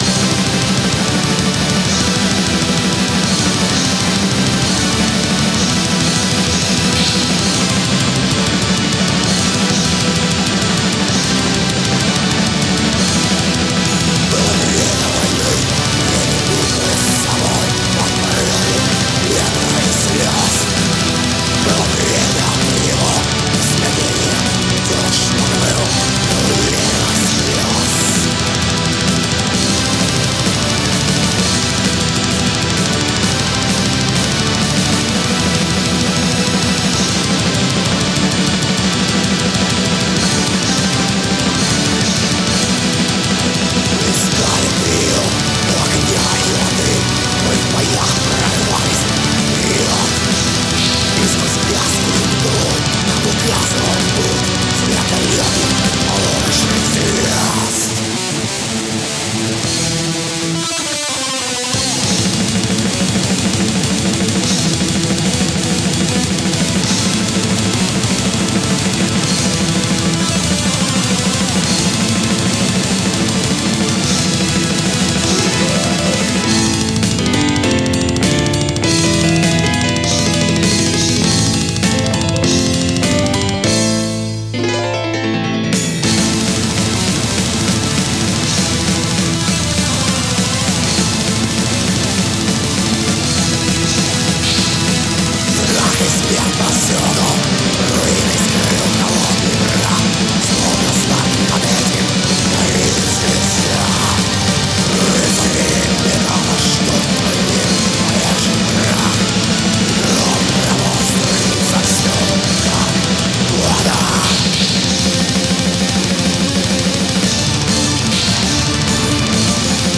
Pagan Folk Metal